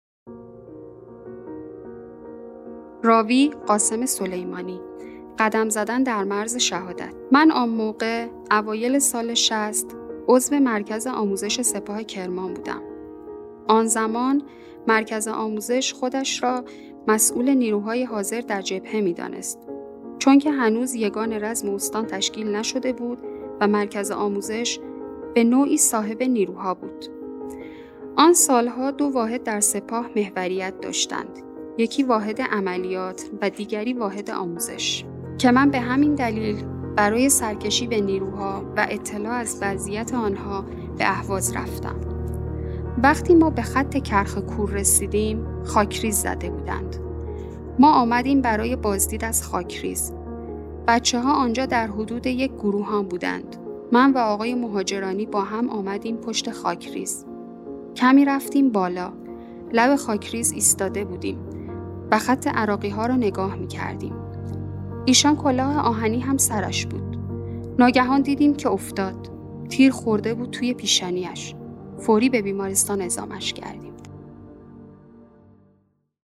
راوی: قاسم سلیمانی